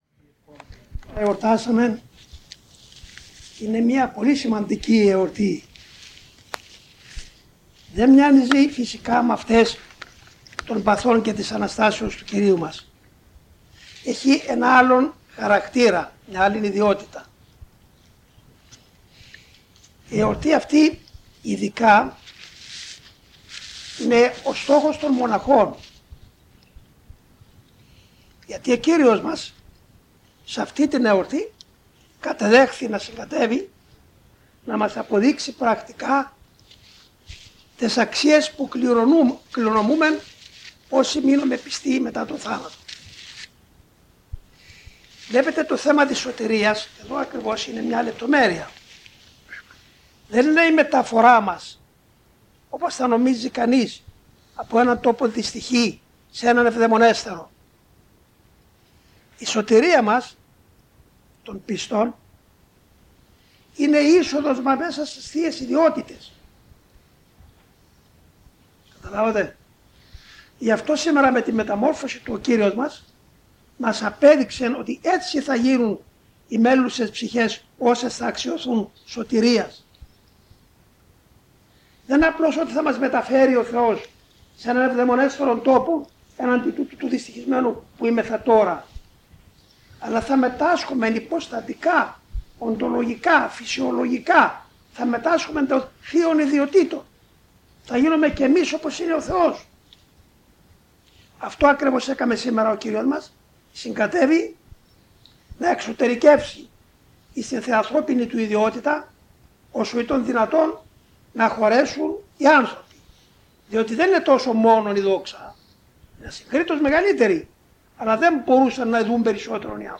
6/8/1993 Σύναξη της αδελφότητος